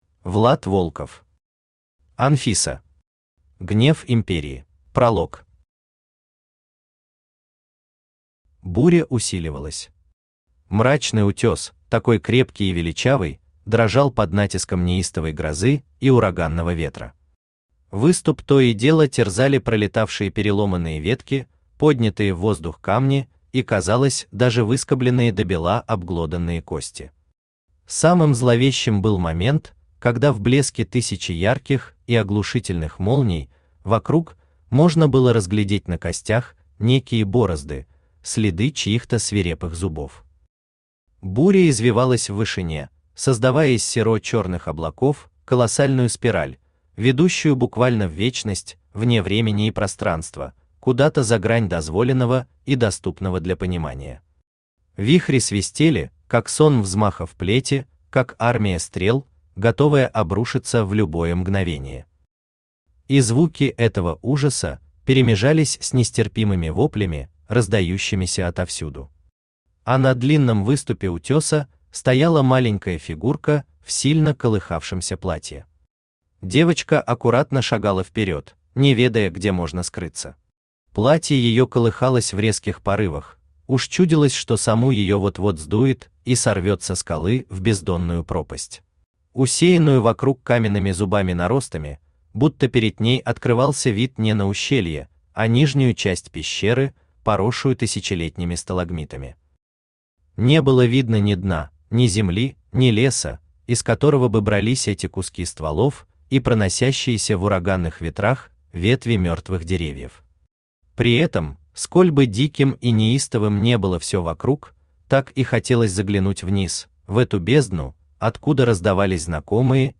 Аудиокнига Анфиса. Гнев Империи | Библиотека аудиокниг
Гнев Империи Автор Влад Волков Читает аудиокнигу Авточтец ЛитРес.